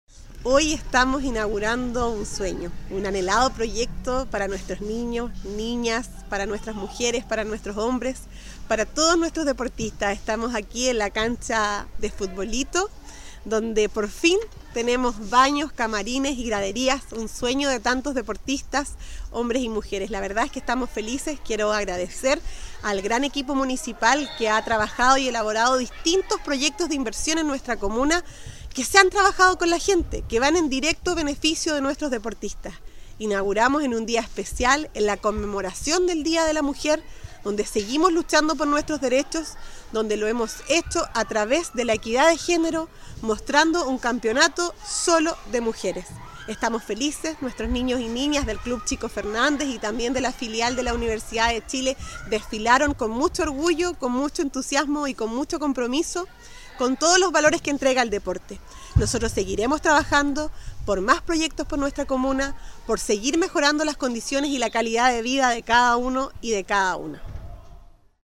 Esta iniciativa consideró una intervención de 140 metros cuadrados que incluyen amplias instalaciones con 2 camarines, 2 baños, 1 baño con acceso universal, 1 bodega y 1 espaciosa gradería, que permitirán a la comunidad realizar en óptimas condiciones sus actividades deportivas y recreativas bajo techo en este recinto ubicado a un costado del Parque Los Molinos, tal como lo destacó la alcaldesa de la comuna, Javiera Yáñez: